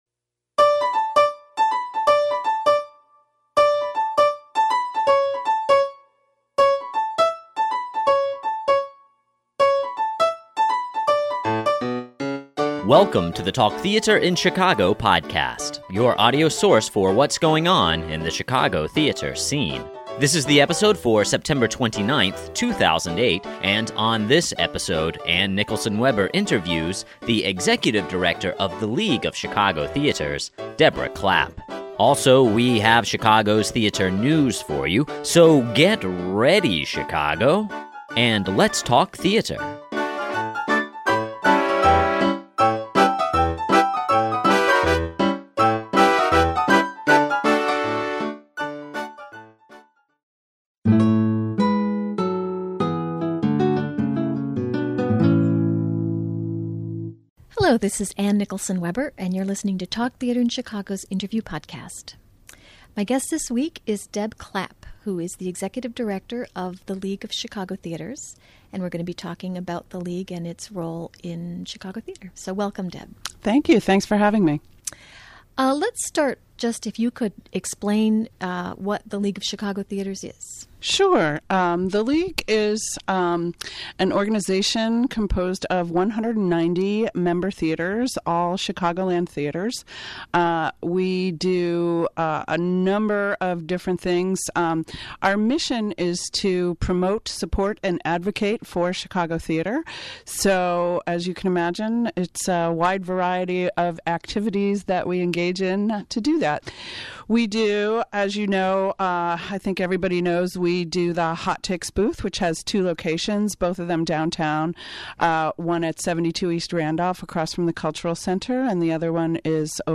Interview Podcast